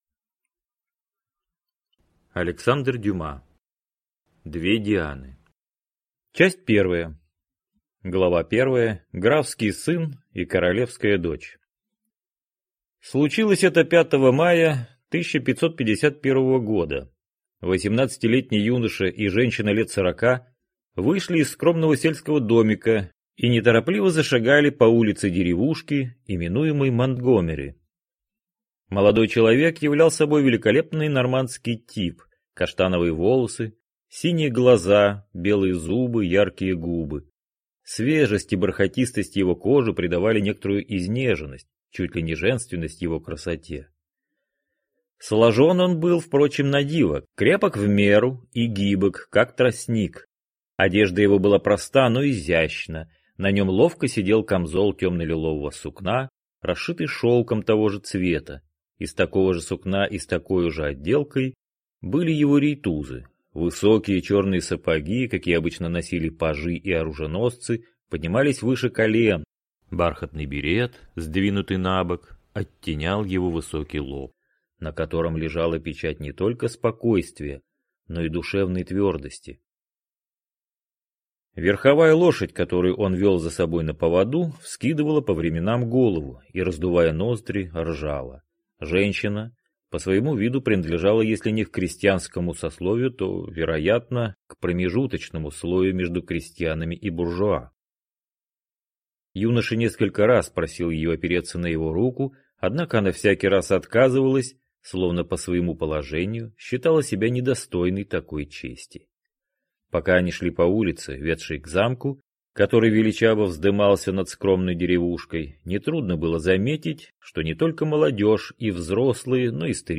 Аудиокнига Две Дианы | Библиотека аудиокниг